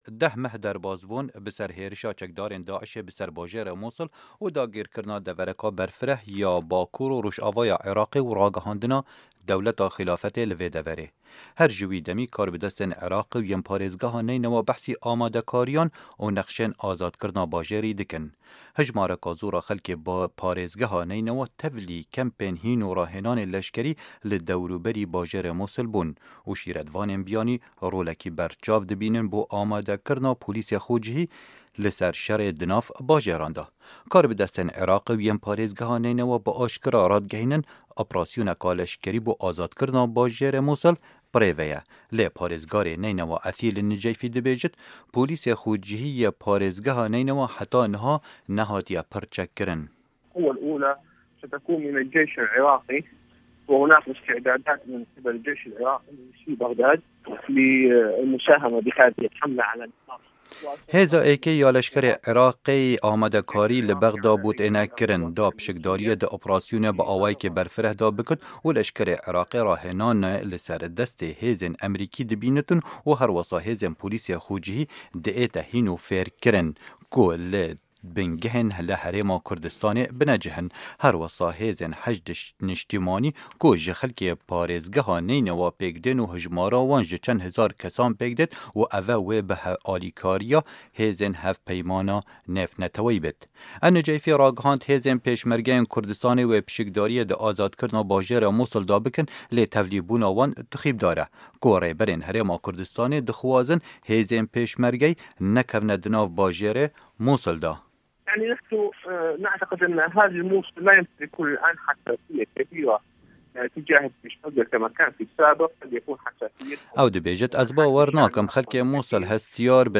Di hevpeyvînekê de ligel Dengê Amerîka, parêzgarê Mûsilê dibêje, amadekarî jibo rizgarîkirina Mûsilê ji dest çekdarên Dewleta Îslamî (Daîş) tên kirin.